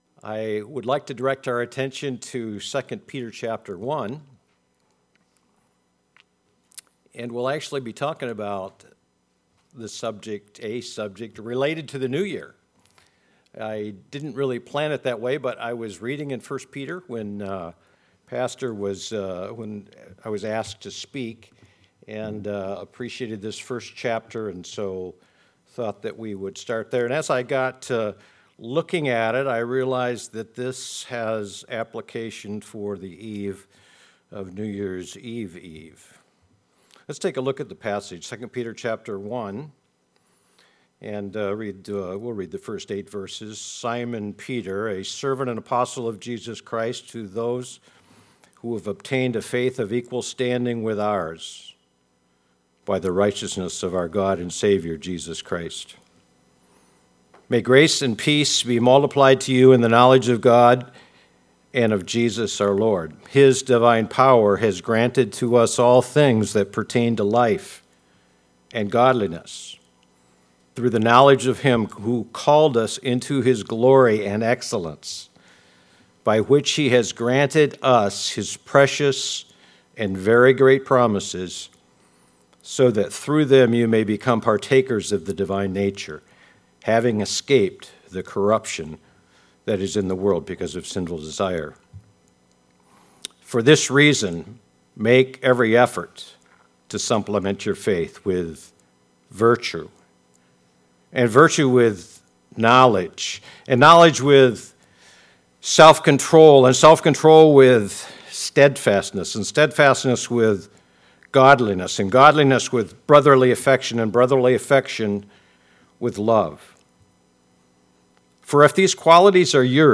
2 Peter 1:1-8 Service Type: Sunday Morning Bible Text